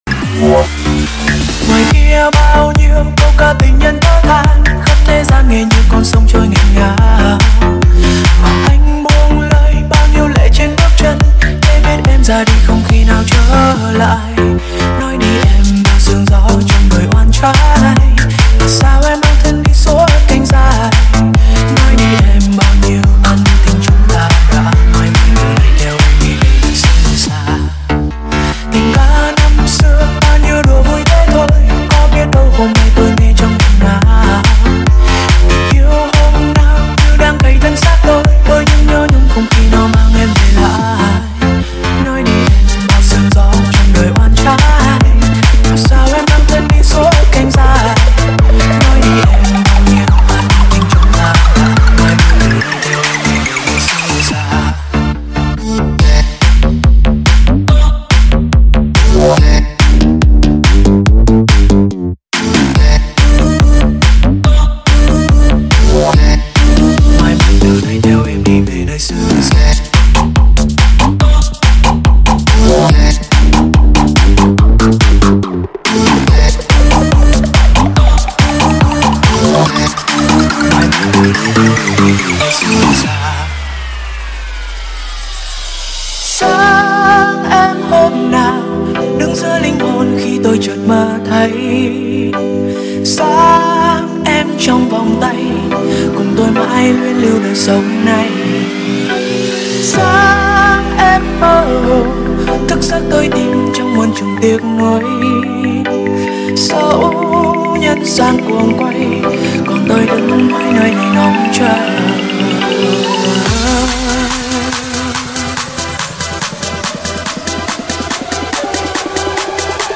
Máy Băn Vít DEVON 5767 Sound Effects Free Download